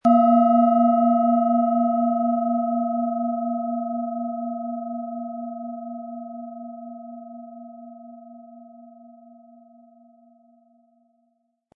Tibetische Kopf-Herz- und Bauch-Klangschale, Ø 11 cm, 100-180 Gramm, mit Klöppel
Beim Aufnehmen für den Shop spielen wir die Klangschale an und probieren aus, welche Teile des Körpers aktiviert werden.
HerstellungIn Handarbeit getrieben
MaterialBronze